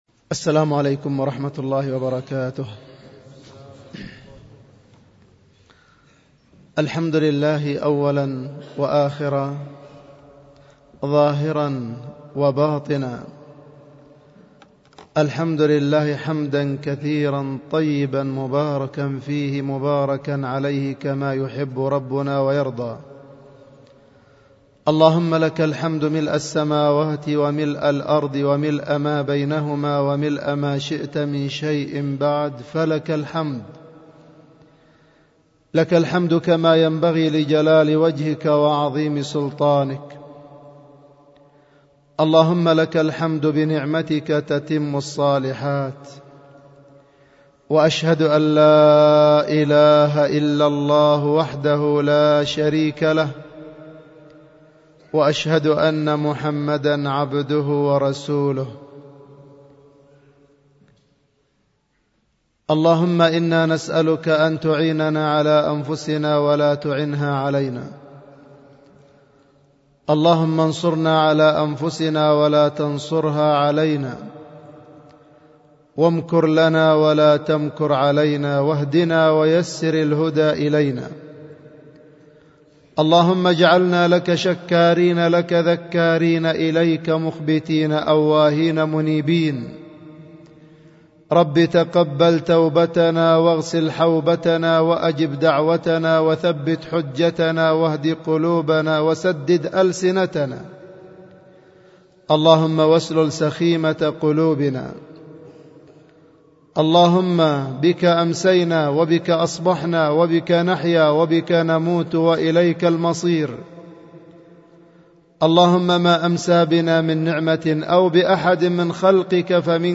ألقيت في دار الحديث بوادي بنا السدة